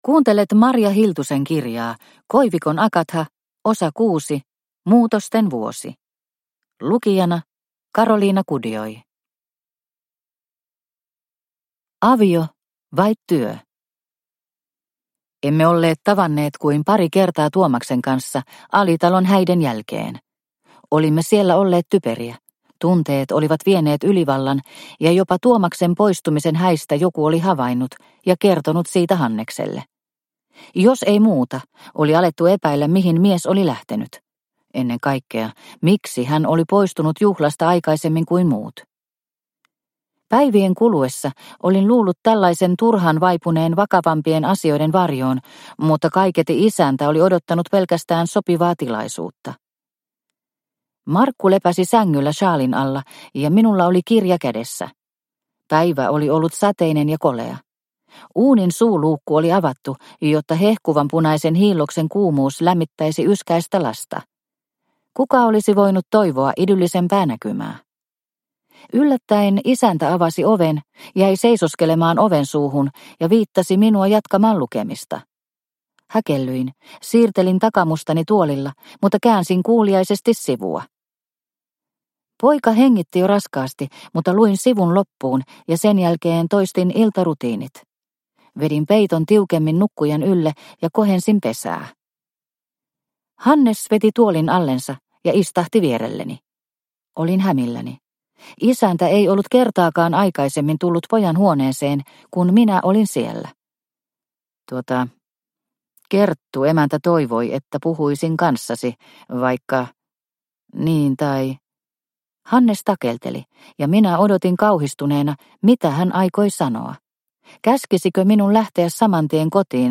Muutosten vuosi – Ljudbok – Laddas ner